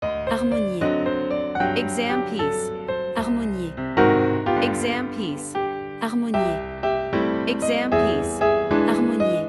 Professional-level Piano Exam Practice Materials.
• Vocal metronome and beats counting